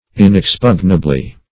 Search Result for " inexpugnably" : The Collaborative International Dictionary of English v.0.48: Inexpugnably \In`ex*pug"na*bly\, adv.
inexpugnably.mp3